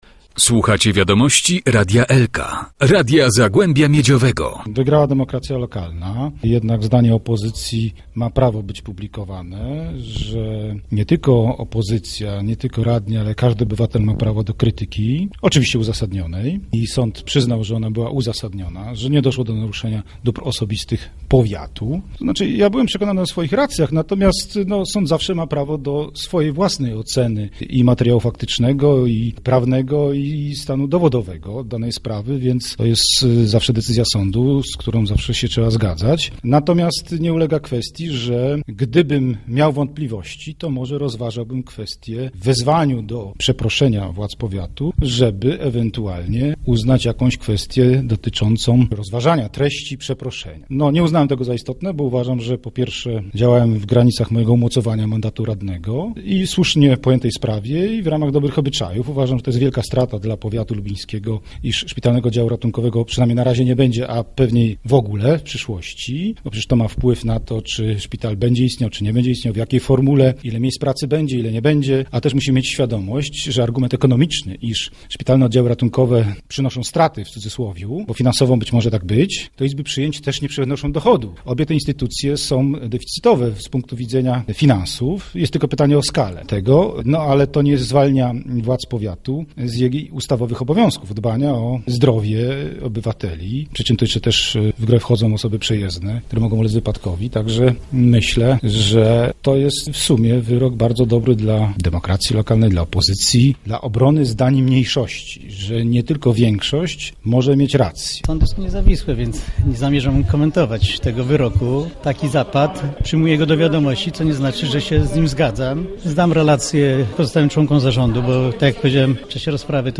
Radny Tadeusz Maćkała; starosta powiatu lubińskiego, Adam Myrda: